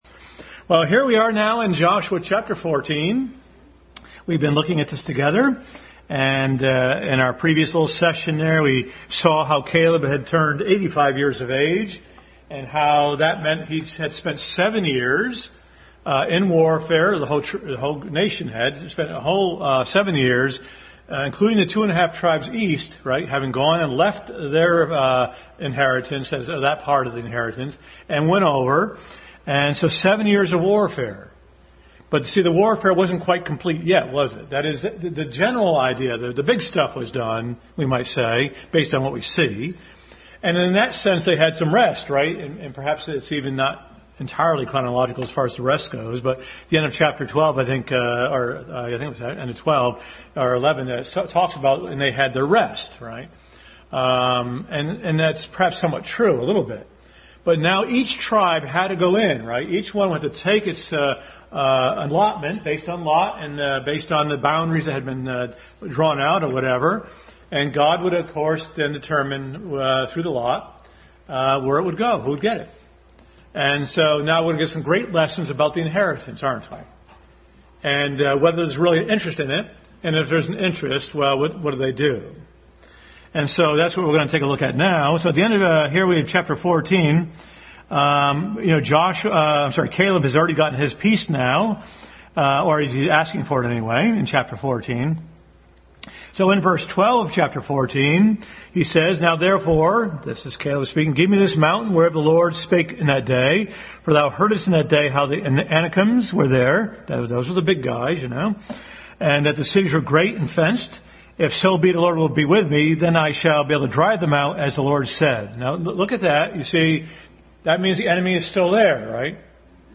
Joshua 14-17 Overview Passage: Joshua 14-17 Service Type: Family Bible Hour Family Bible Hour message.